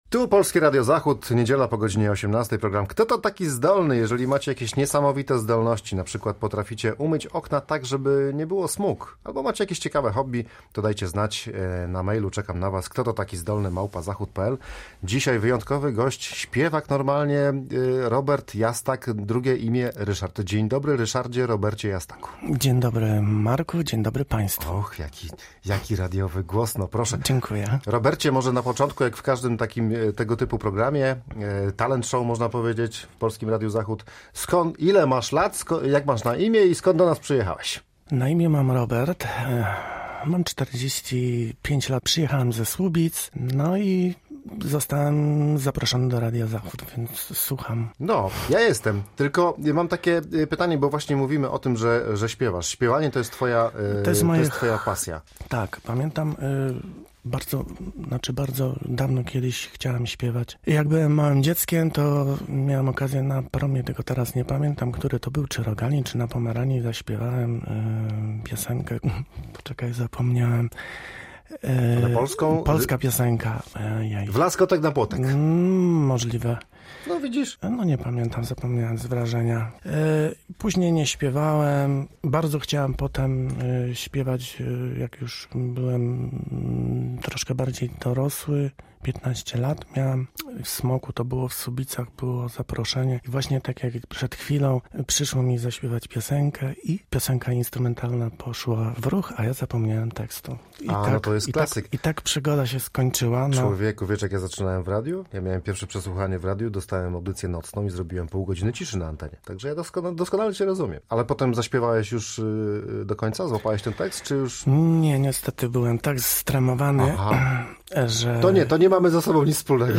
bardzo skromny wokalista, który przyszedł do studia z całą teczką dyplomów!